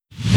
Track 08 - Reverse Kick OS 02.wav